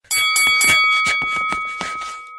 avatar_emotion_taunt.ogg